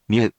We have our computer friend, QUIZBO™, here to read each of the hiragana aloud to you.
In romaji, 「みゅ」 is transliterated as 「myu」which sounds like ‘mew’ and may be recognisable from the first half of the name of the P☆kemon known as ‘Mewtwo’